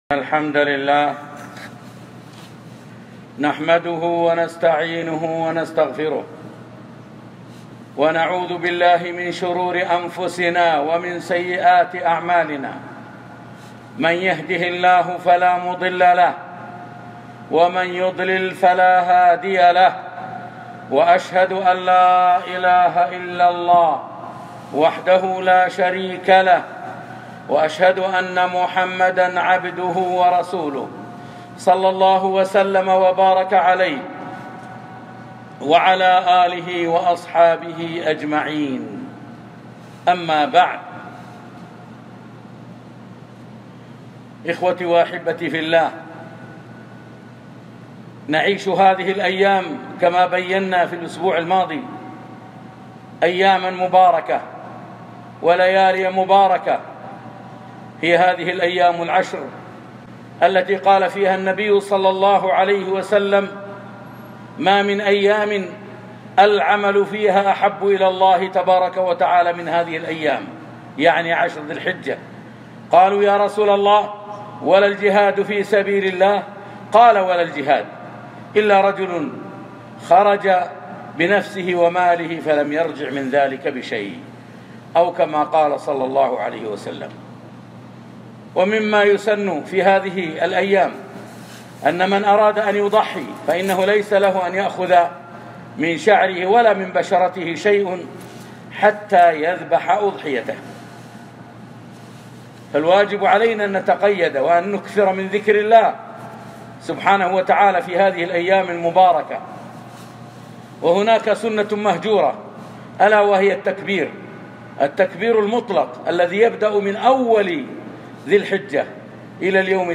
خطبة - الأحكام المتعلقة بعشر ذي الحجة (الأضحية ، الذكر ، صيام يوم عرفة)